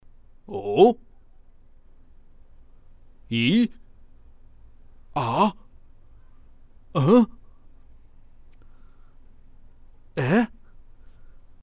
惊叹语气词音效免费音频素材下载